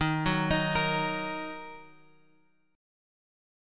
D#m chord {x 6 4 x 4 6} chord
Dsharp-Minor-Dsharp-x,6,4,x,4,6-8.m4a